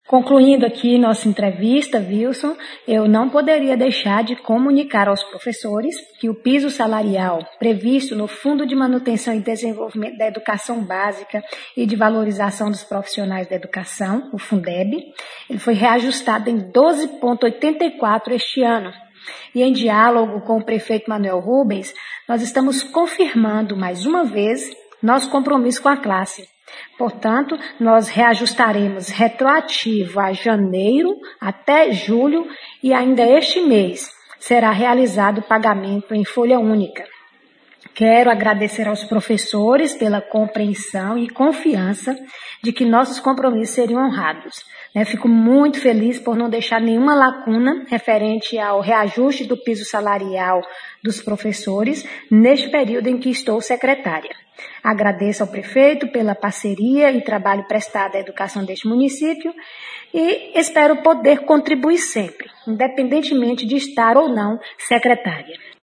Na manhã desta quarta-feira (5), em entrevista ao programa Giro de Notícias, da Rádio Visão FM, a Secretária Municipal de Educação de Palmas de Monte Alto – Vicência Paula, confirmou a informação obtida pela nossa reportagem, que a prefeitura dará um reajuste de 12,84% no Piso Salarial dos professores, previsto no Fundo de Manutenção e Desenvolvimento da Educação Básica e de Valorização dos Profissionais da Educação (Fundeb).